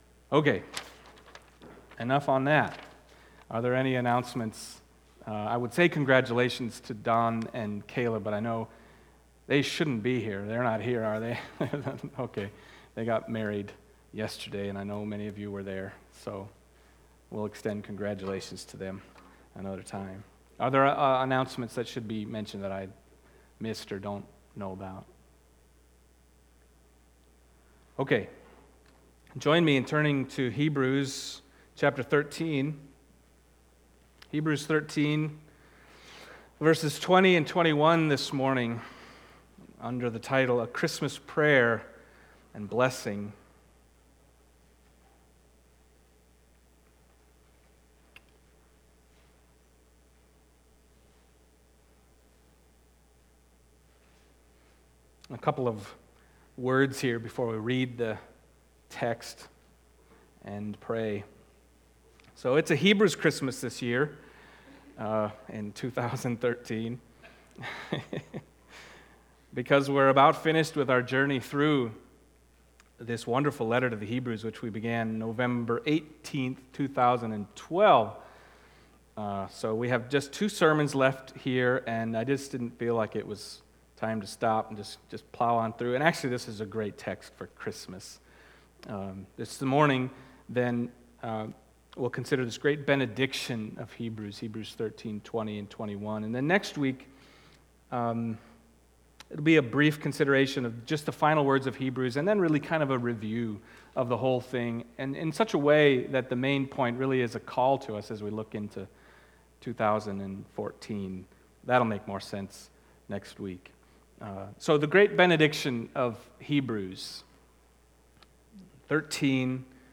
Hebrews Passage: Hebrews 13:20-21 Service Type: Sunday Morning Hebrews 13:20-21 « Shepherds